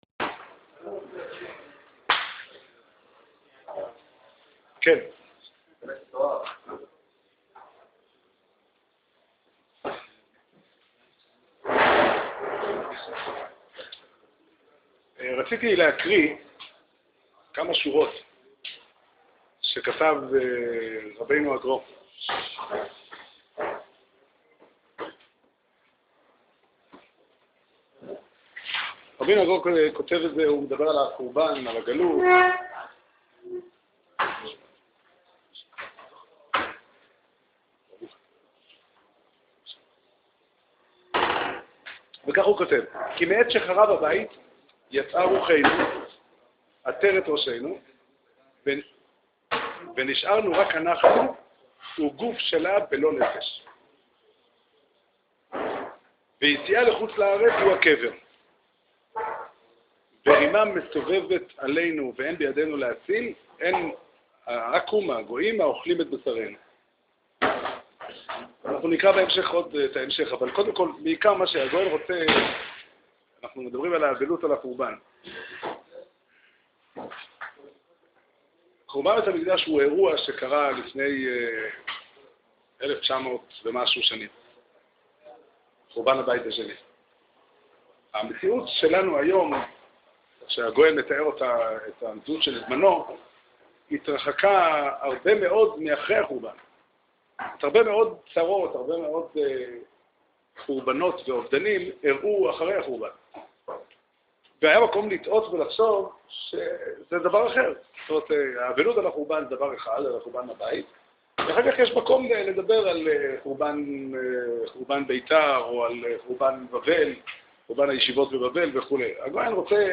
שיעור שנמסר בבית המדרש פתחי עולם בתאריך כ"ג תמוז תשע"ד